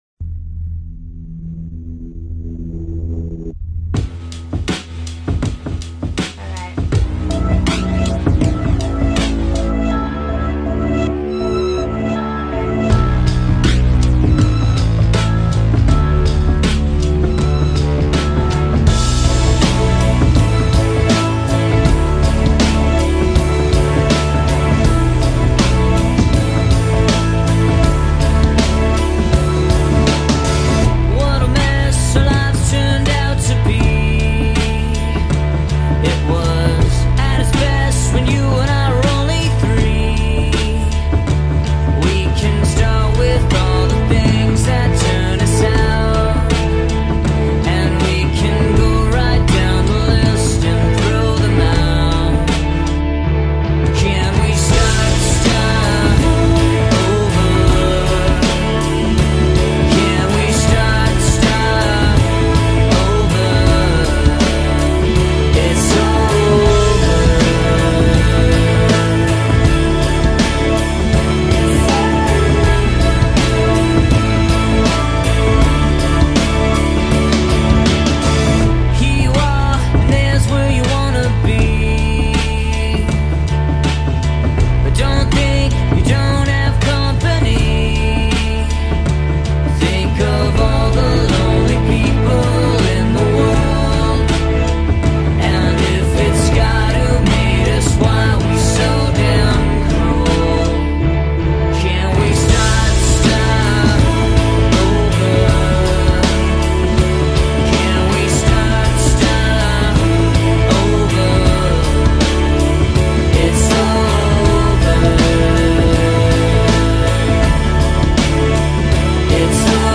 Kinfa sad but both go hand in hand (to me)